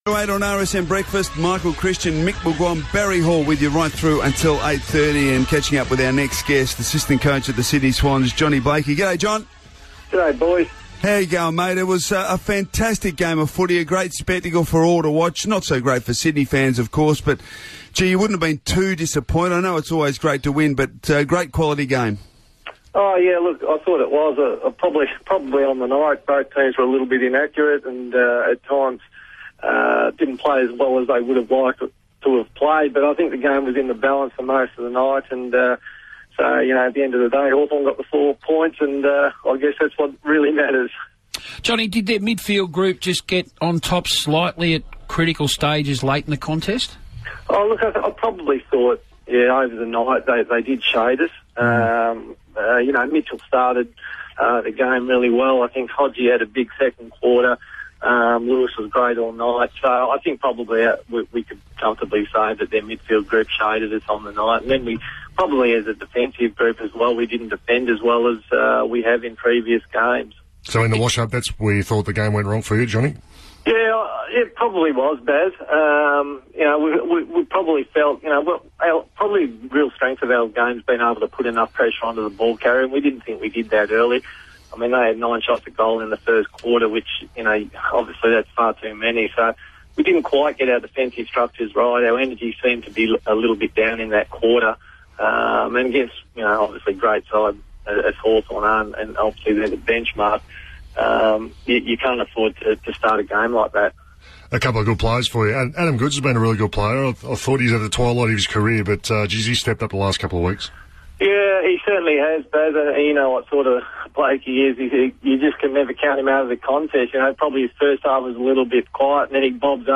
spoke to Radio Sports National's breakfast program on Monday July 28, 2014